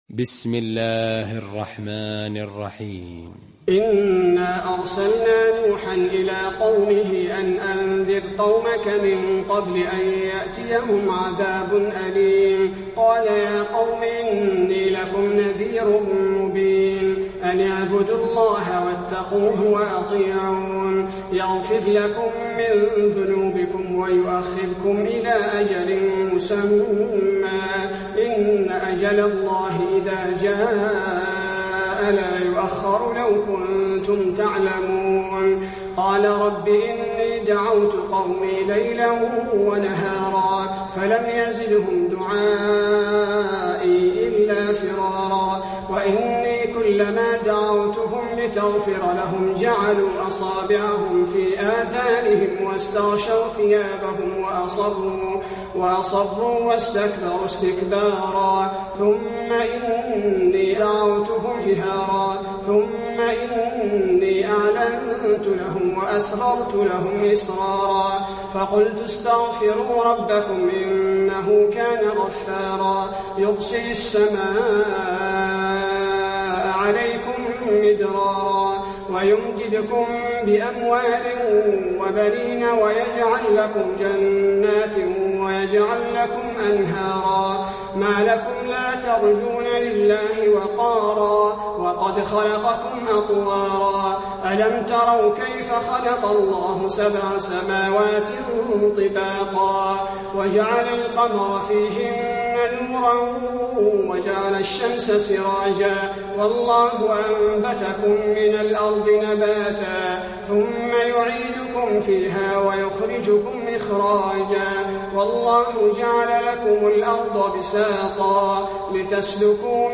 المكان: المسجد النبوي نوح